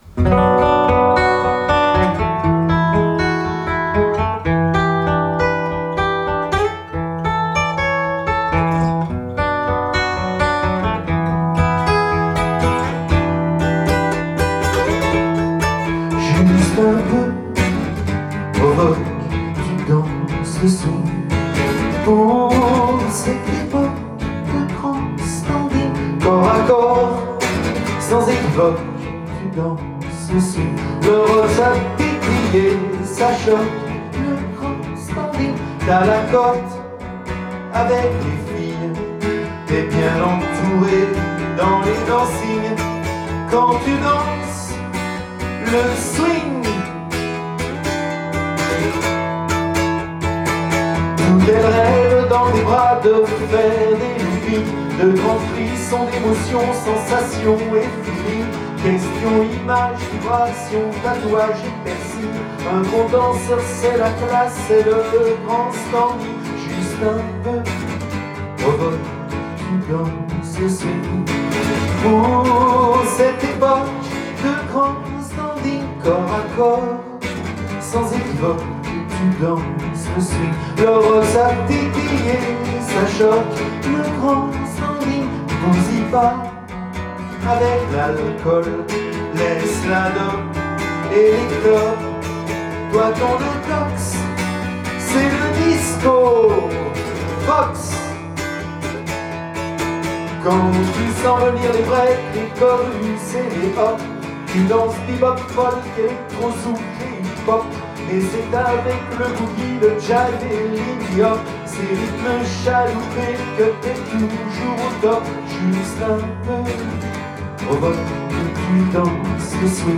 Rock jive d’un homme qui détient la clé